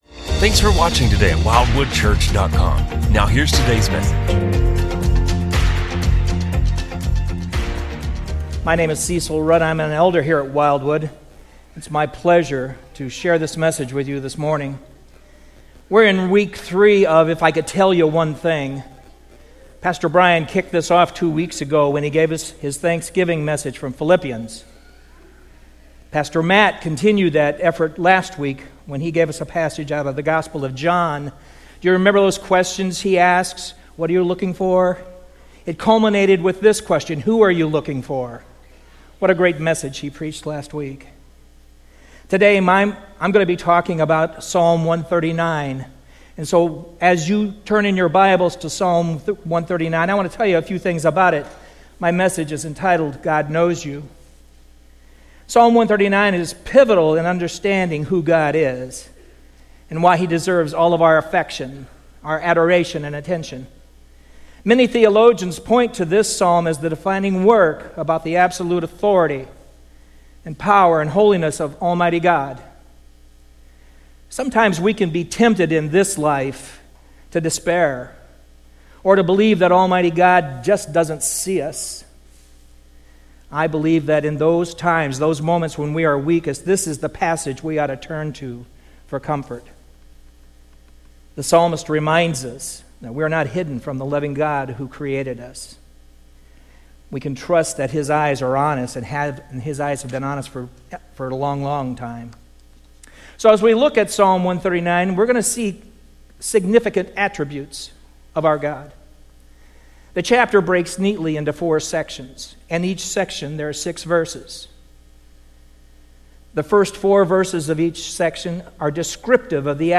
Join us for an uplifting sermon